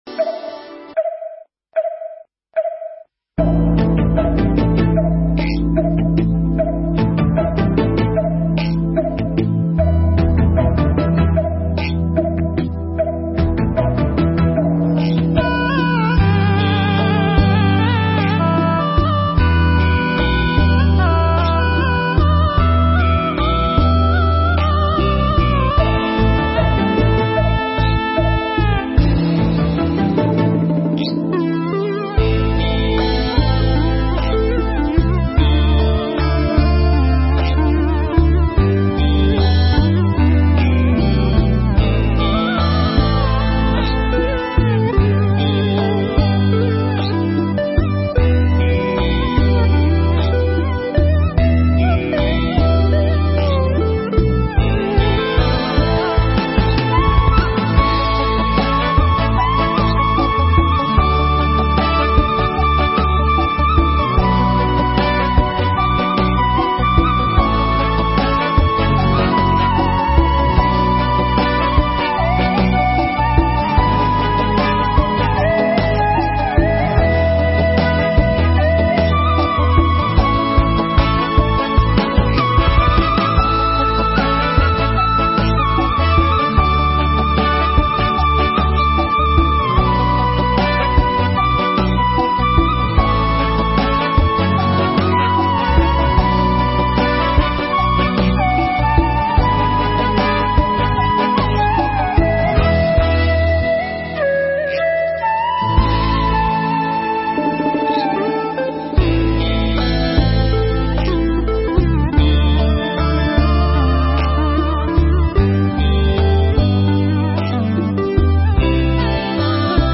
Mp3 Thuyết Pháp Nuôi Dưỡng Nụ Cười (KT88)
giảng trong khóa tu Một Ngày An Lạc lần thứ 88 tại Tu Viện Tường Vân